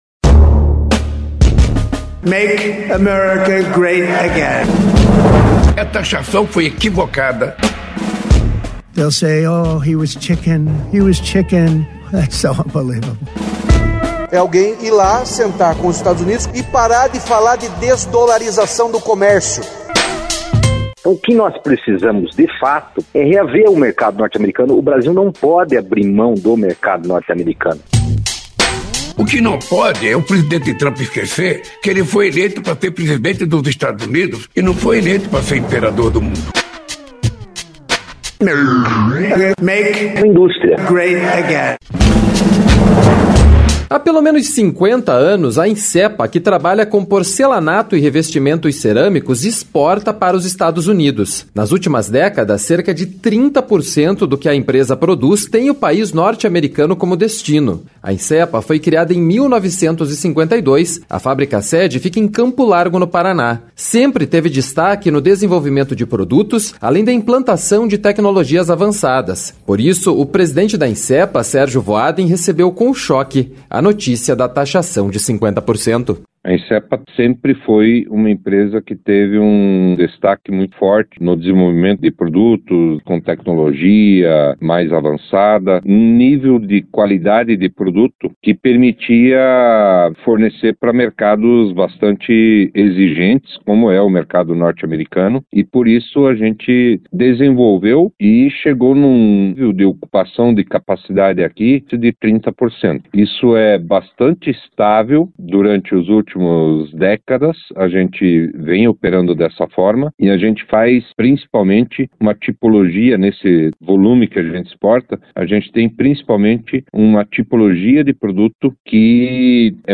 Reportagem 1 – Quando as portas se fecham